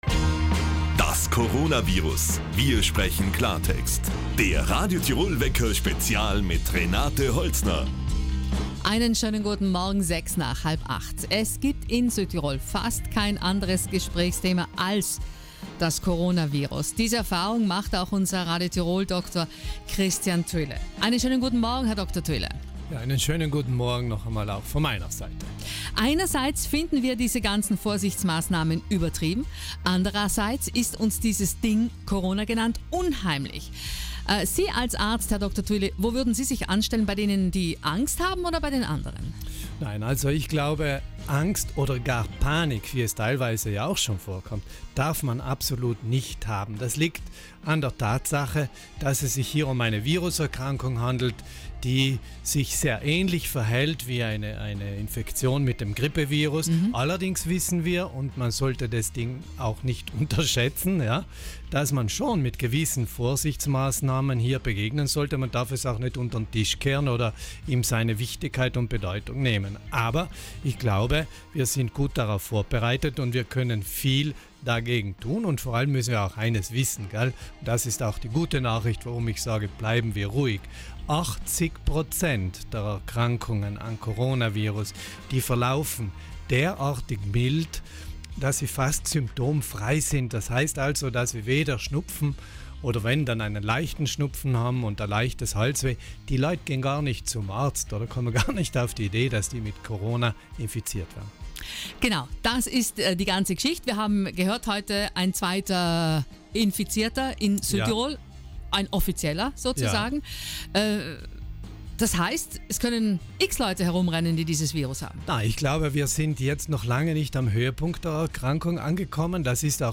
zwischen 7:30 und 8:00 Uhr live Ihre Fragen zum Coronavirus beantwortet.